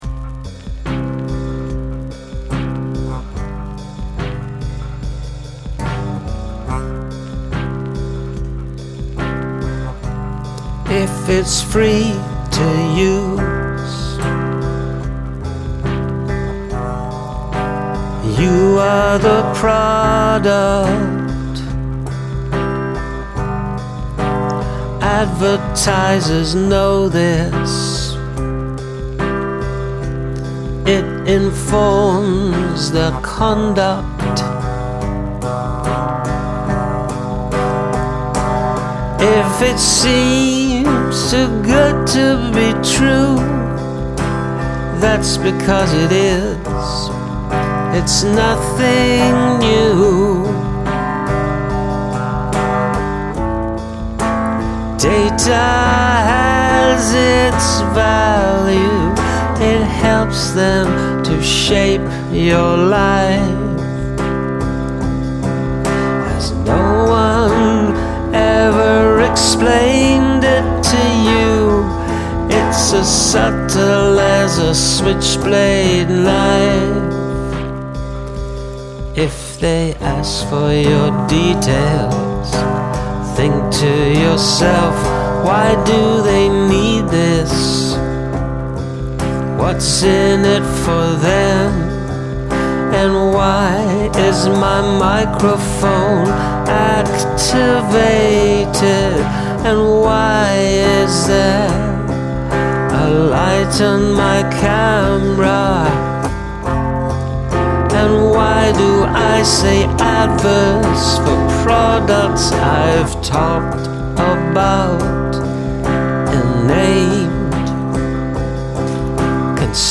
😬 Great alt rock sound
This has such a "Kinks" vibe, (I'm a Kinks fan) love your vocals and instrumentation.